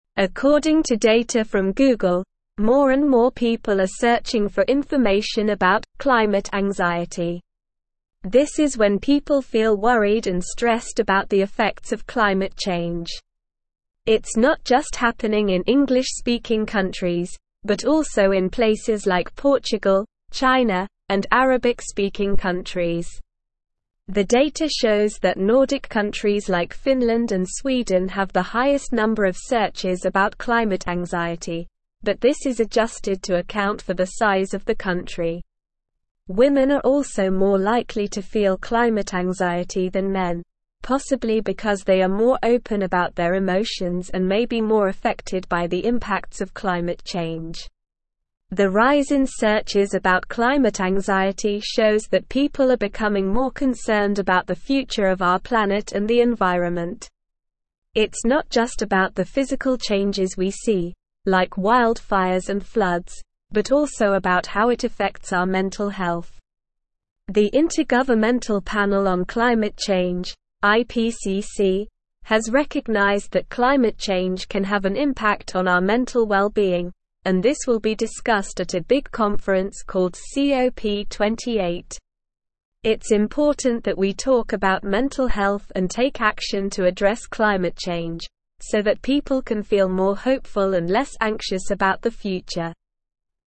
Slow
English-Newsroom-Upper-Intermediate-SLOW-Reading-Rising-Search-Queries-Show-Womens-Climate-Anxiety-Increase.mp3